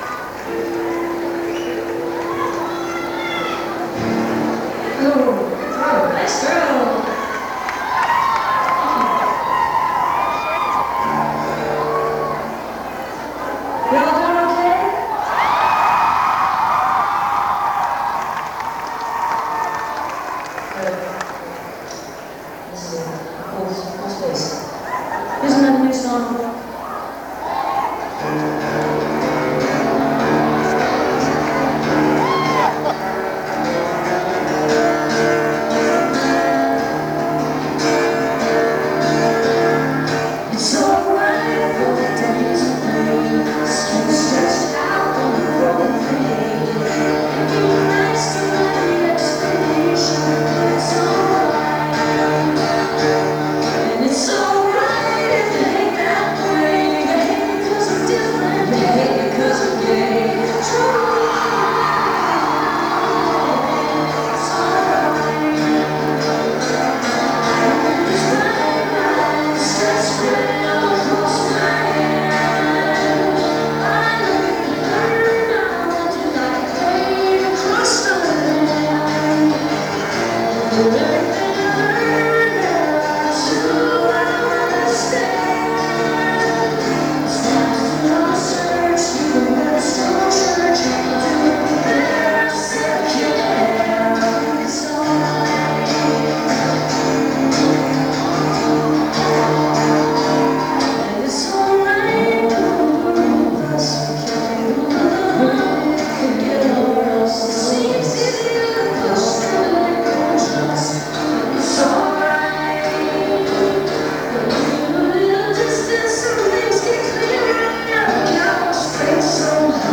(band show)